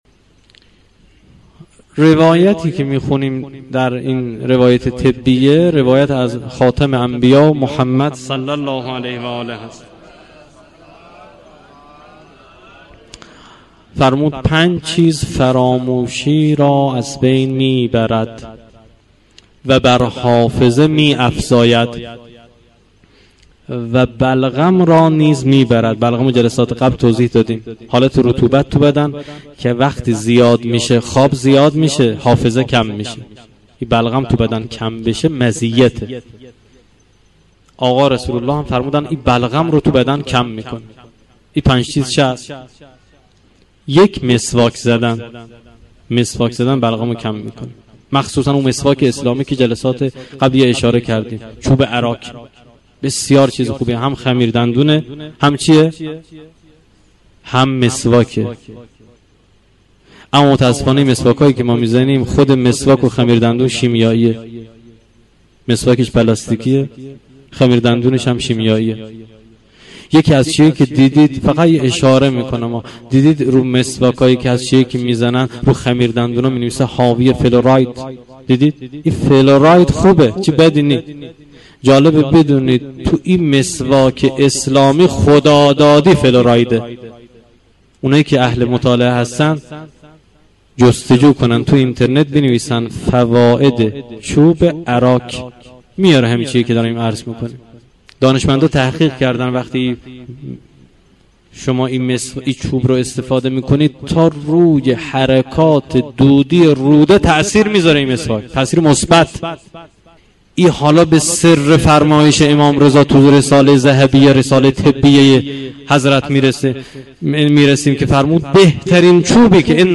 دسترسی به فایل صوتی سخنرانی های مسجد سبد از طریق پیام رسان های سروش، گپ، ایتا و تلگرام
سخنرانی های طب اسلامی - فصل بندی شده